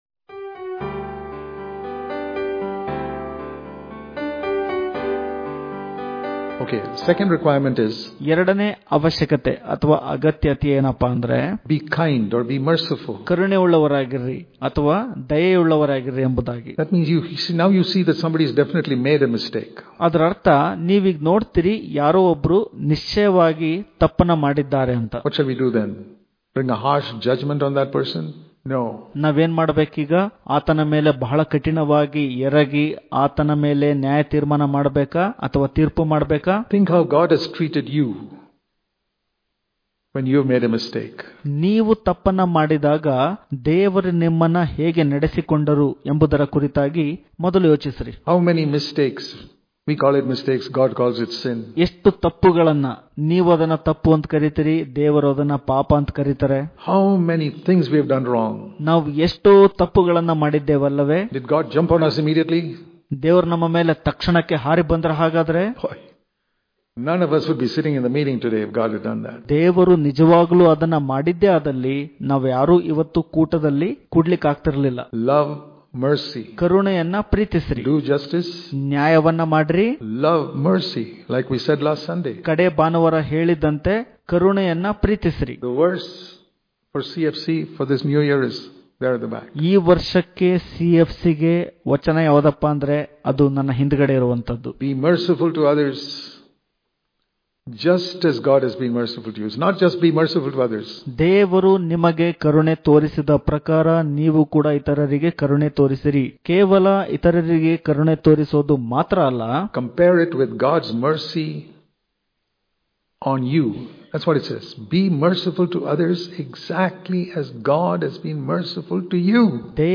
July 9 | Kannada Daily Devotion | Love Mercy Daily Devotions